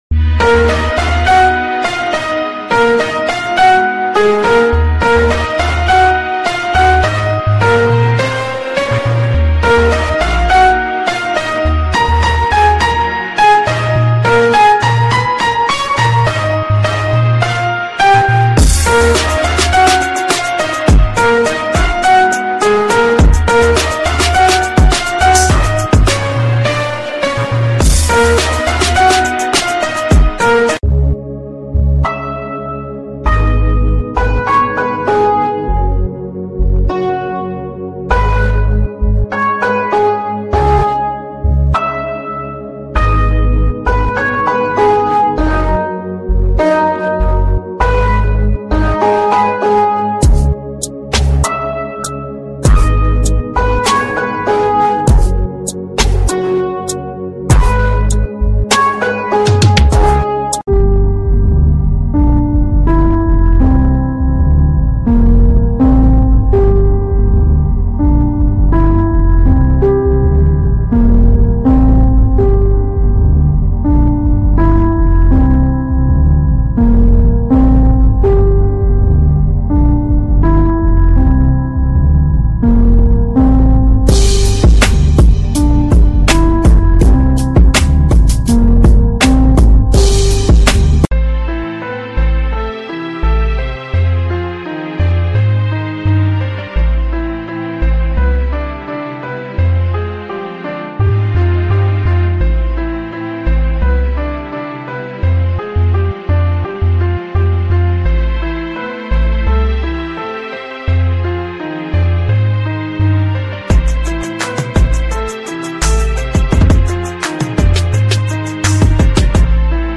Venice, Italy Walking Tour ｜ sound effects free download